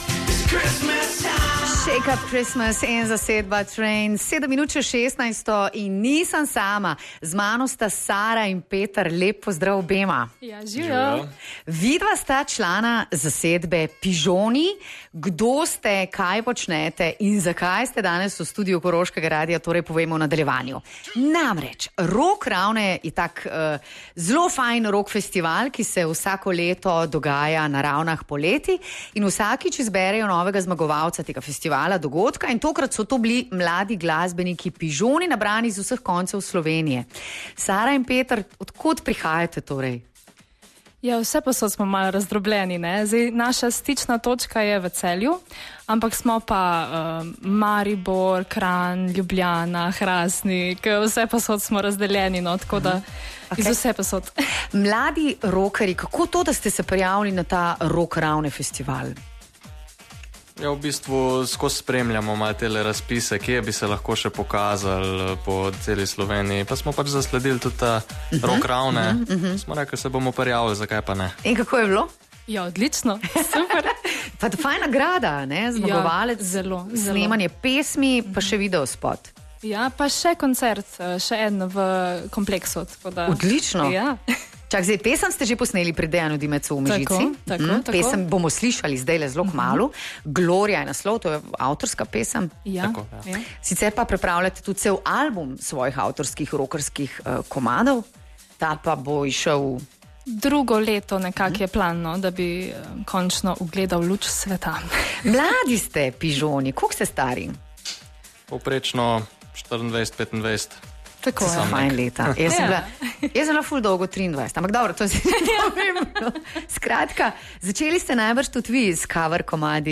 Čeprav je od letošnjega poletnega festivala Rock Ravne minilo že precej časa, smo v našem studiu gostili mlade zmagovalce Pižoni. Gre za rock band mladih akademskih glasbenikov, nabranih iz vseh koncev Slovenije.
Poslušajte pogovor in pesem v živo.